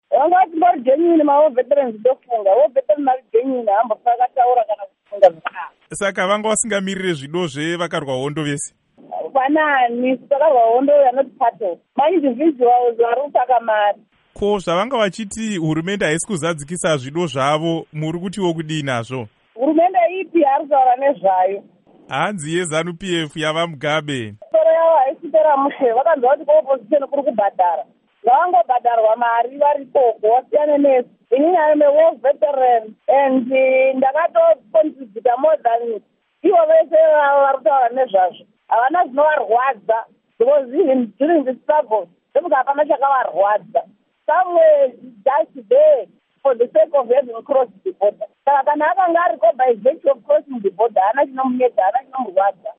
Hurukuro naAmai Mandi Chimene